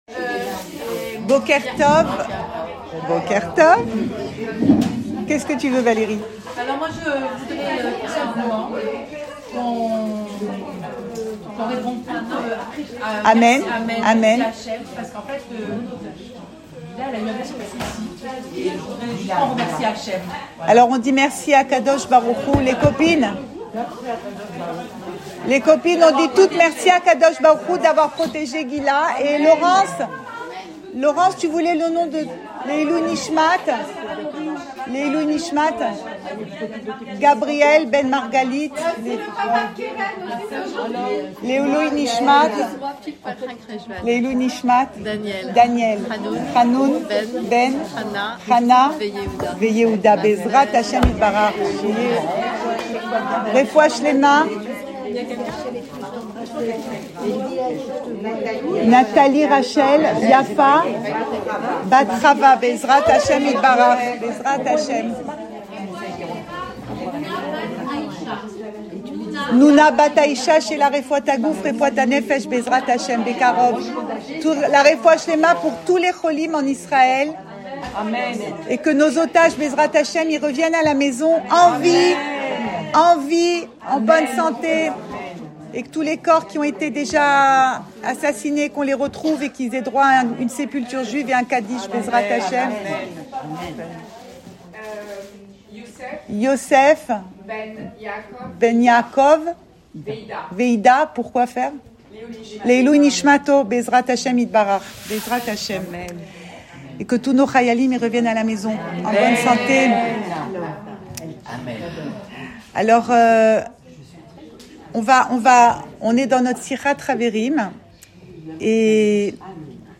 » Cours audio Emouna Le coin des femmes Pensée Breslev - 6 novembre 2024 1 mai 2025 « Tiens bon ! » Enregistré à Tel Aviv